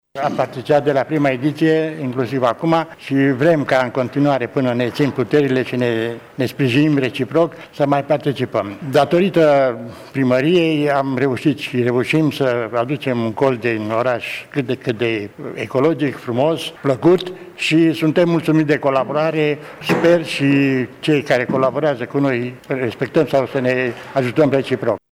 Imediat după incident, cei trei câini au fost transportaţi la Adăpostul de animale din Tîrgu-Mureş, unde se află şi în prezent, spune seful Direcţiei Sanitar Veterinare Mureş, Vasile Oprea.